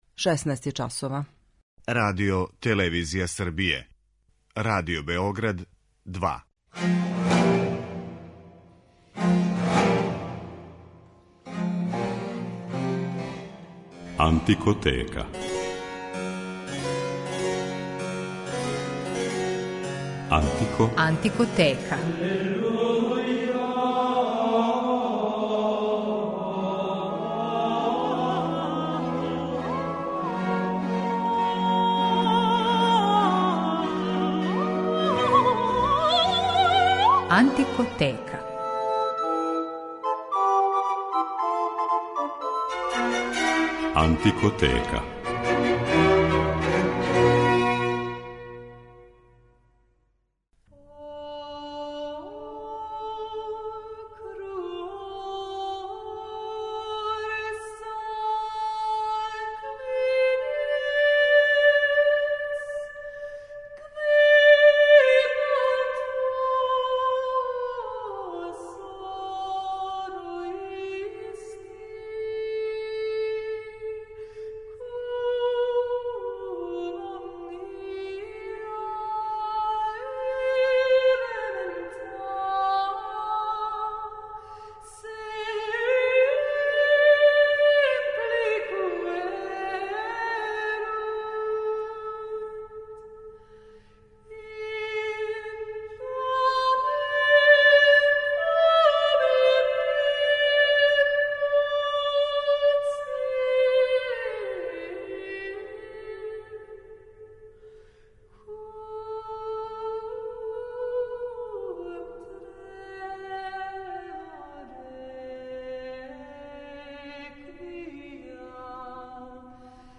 Била је мистик, песник, композитор, космолог, проповедник, писала је текстове о медицини и природи. Хилдегард фон Бинген је посвећена данашња емисија у којој ћете слушати одломке њеног мистерија „Ordo Virtutum", антифоне, секвенце и лауде Светој Урсули.